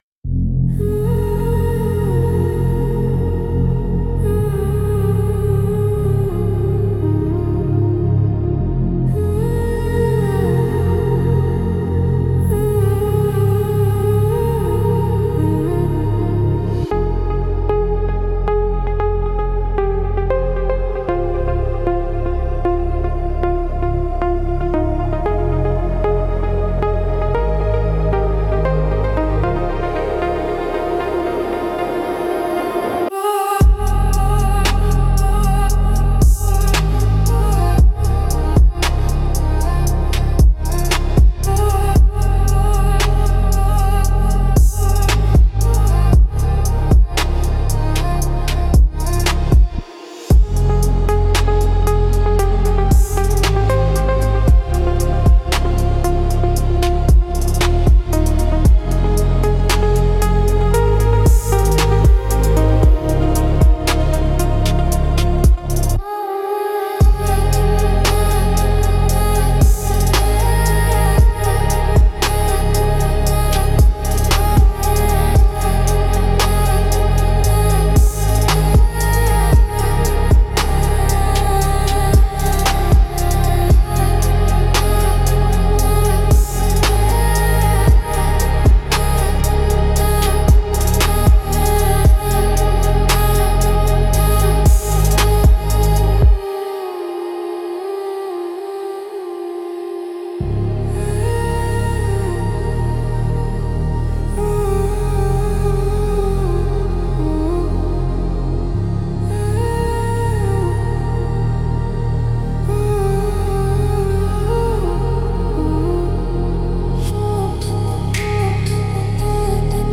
Instrumental - Echoes of You - 3.12